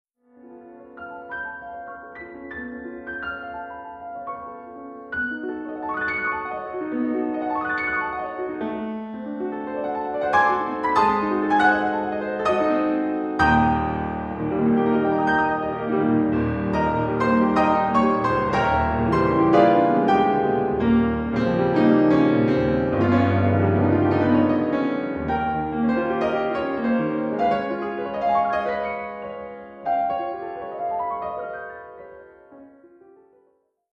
Australian classical music
impressionist piano favourites
Classical, Keyboard